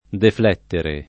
defl$ttere] v.; defletto [defl$tto] — pass. rem. deflessi [defl$SSi] o deflettei [